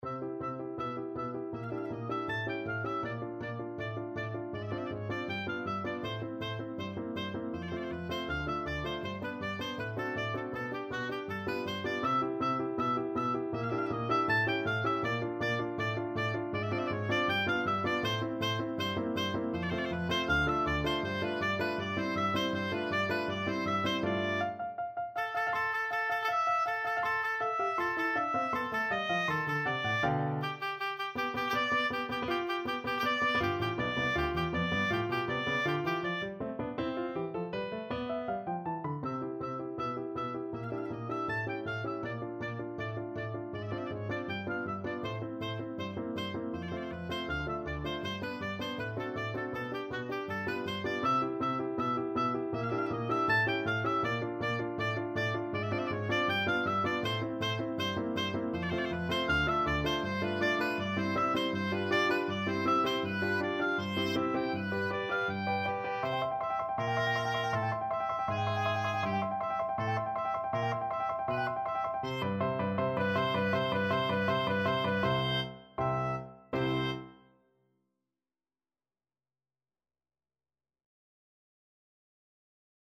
Oboe
4/4 (View more 4/4 Music)
C major (Sounding Pitch) (View more C major Music for Oboe )
Molto Allegro = c. 160 (View more music marked Allegro)
Classical (View more Classical Oboe Music)